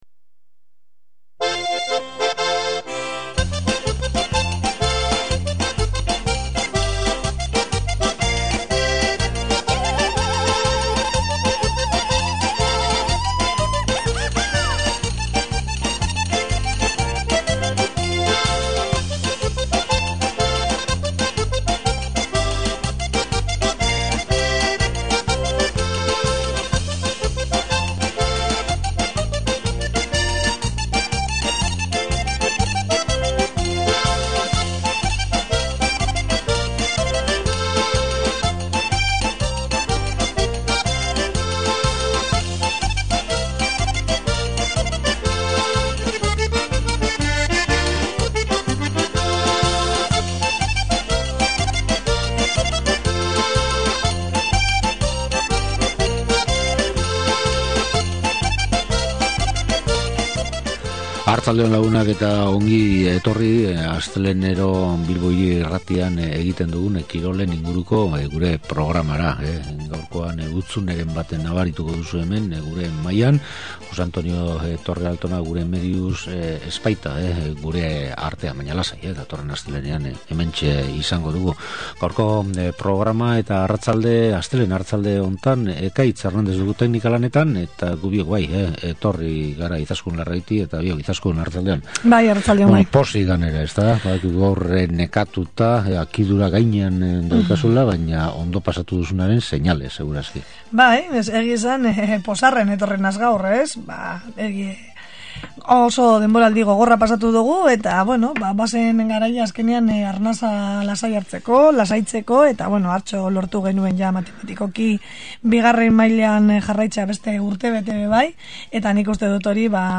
Hori aipatu ostean, Athleticek Riazorren lorturiko garaipena jorratzen aritu dira gure kirol komentaristak. Era berean, saioaren bukaera aldean pilota arloan iragan asteburu honetan izandako norgehiagoka nagusiak komentatu dituzte.